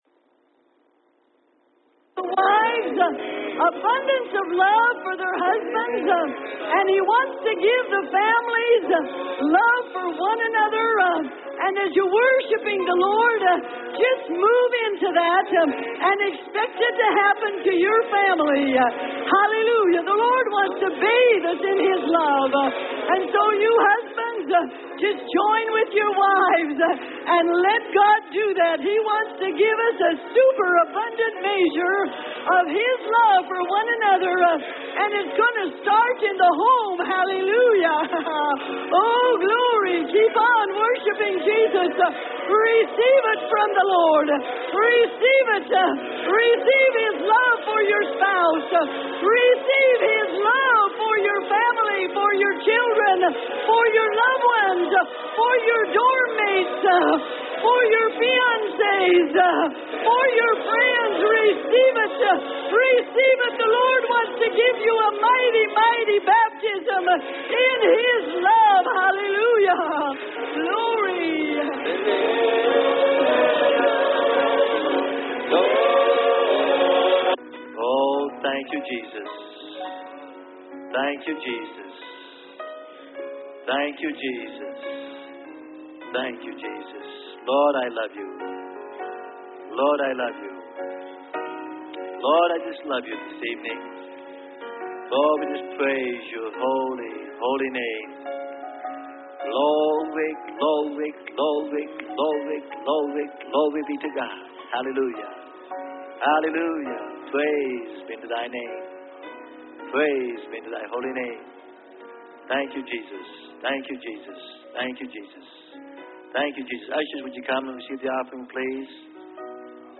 Sermon: How To Appropriate Your Freedom In The Spirit.